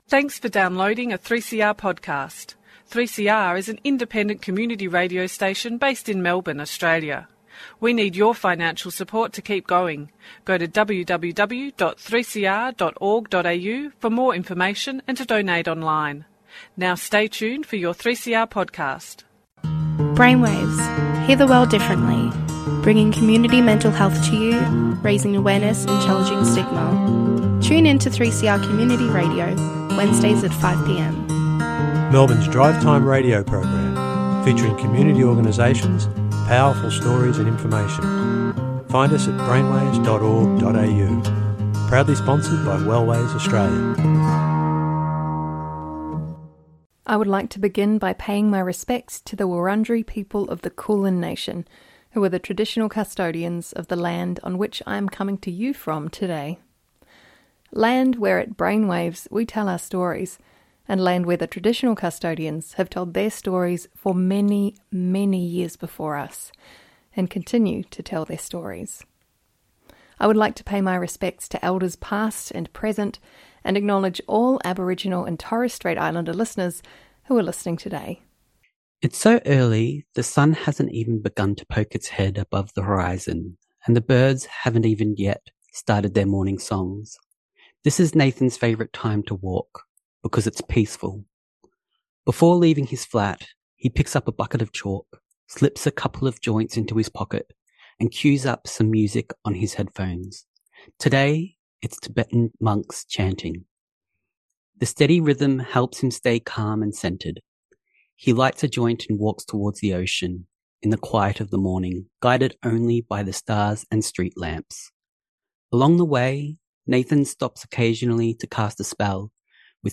Last month, we held our 24th Annual Woodcock Lecture in Melbourne.
Today, on our radio show Brainwaves, we share part one of three, that combined, feature the opening, keynote speech and panel discussion, respectively.